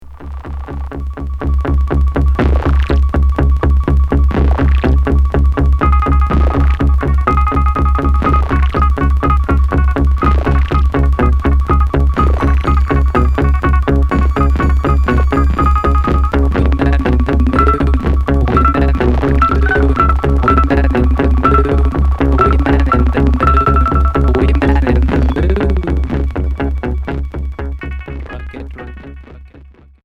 Expérimental